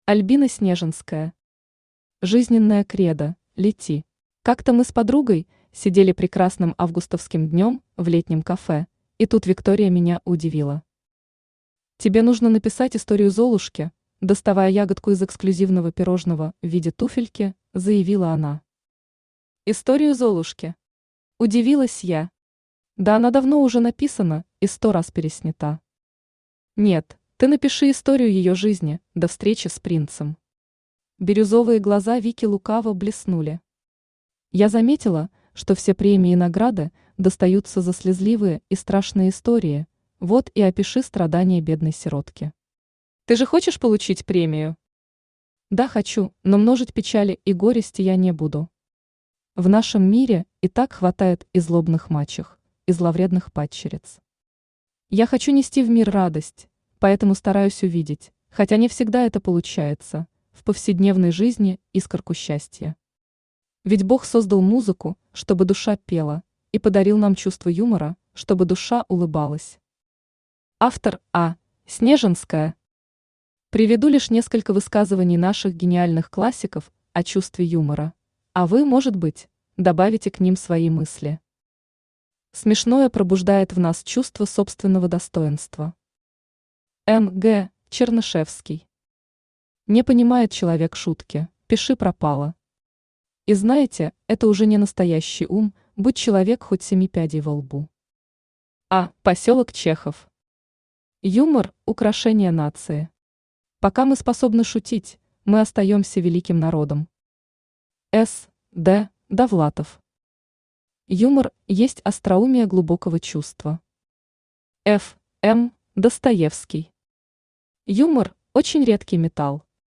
Aудиокнига Жизненное кредо – Лети! Автор Альбина Снежинская Читает аудиокнигу Авточтец ЛитРес.